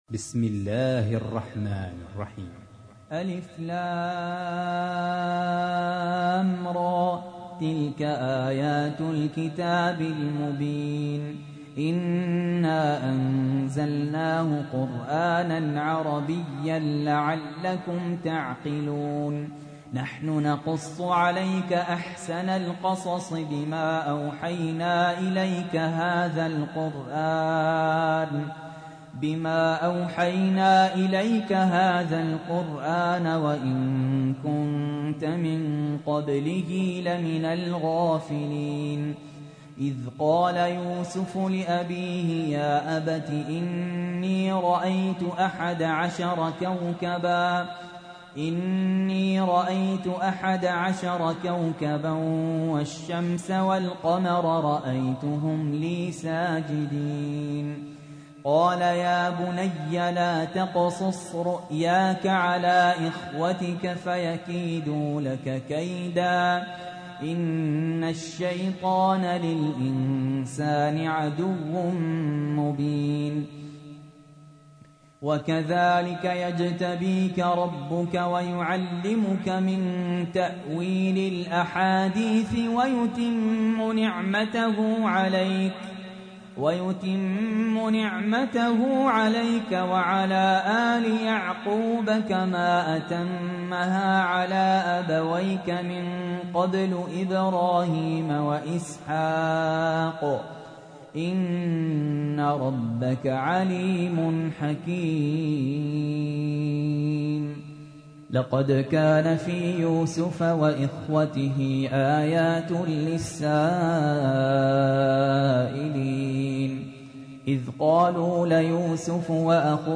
تحميل : 12. سورة يوسف / القارئ سهل ياسين / القرآن الكريم / موقع يا حسين